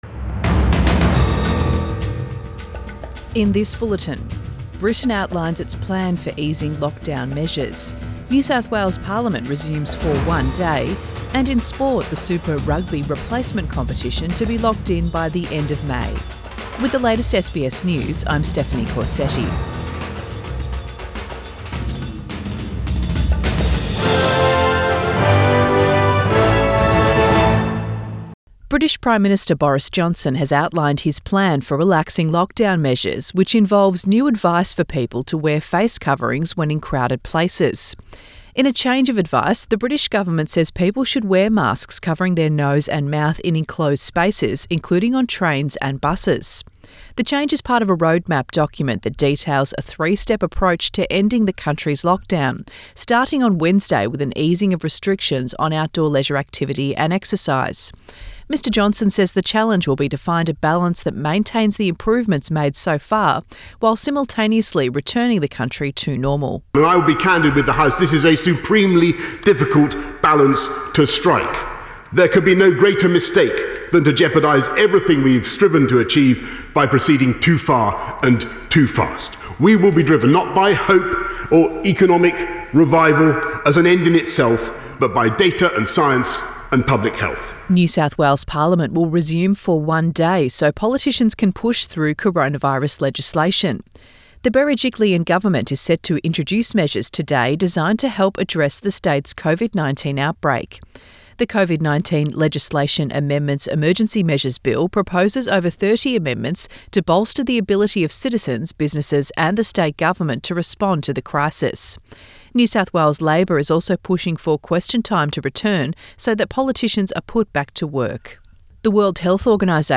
AM bulletin 12 May 2020